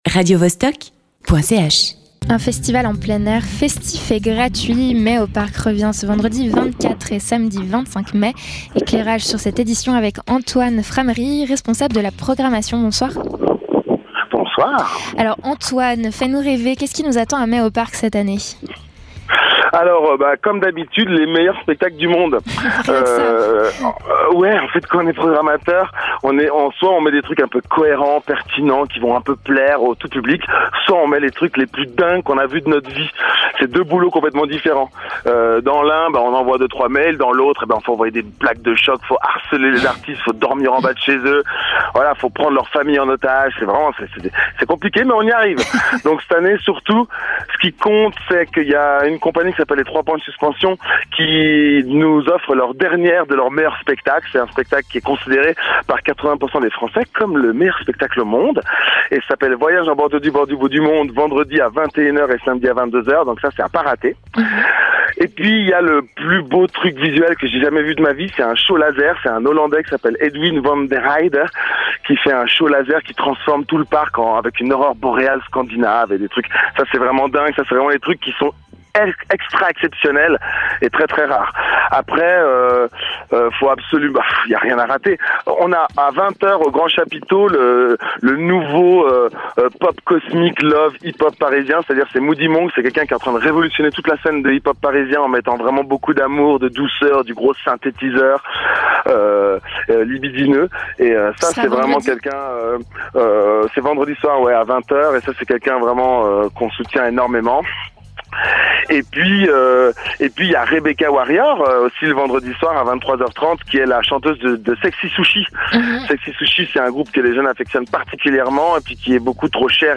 Animation